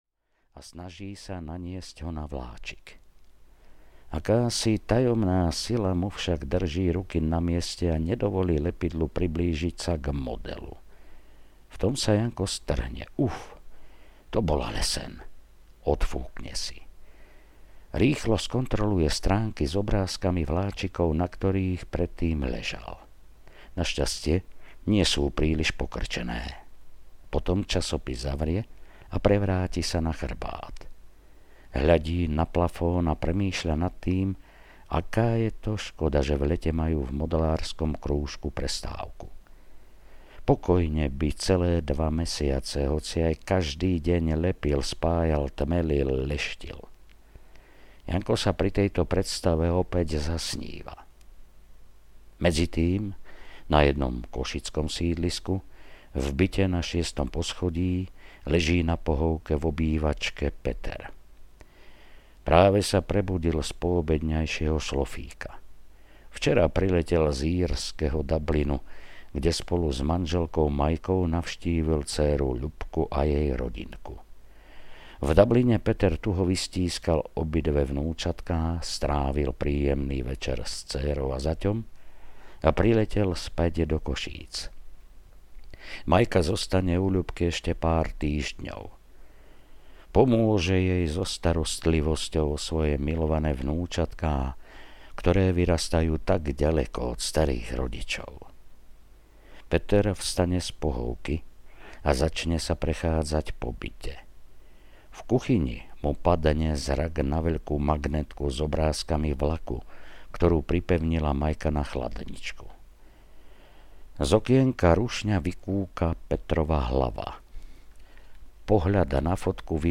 Život na koľajniciach audiokniha
Ukázka z knihy